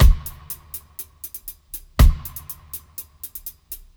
121-FX-04.wav